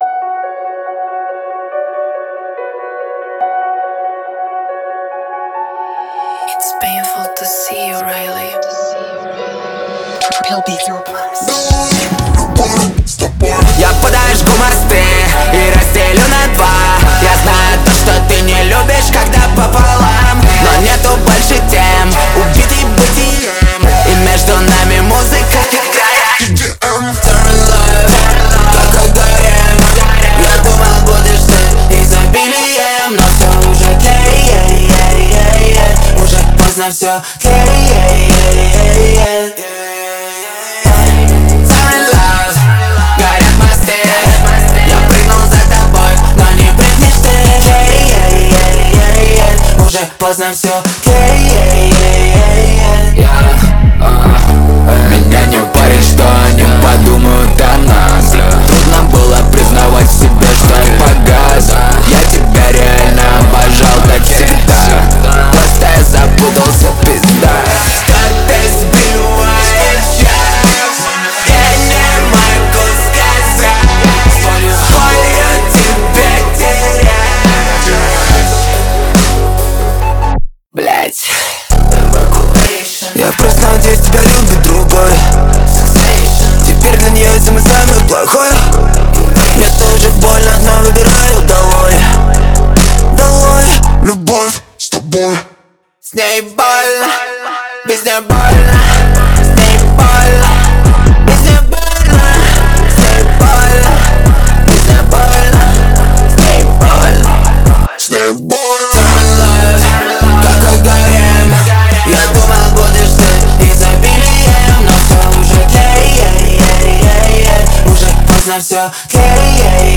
Категория: Рэп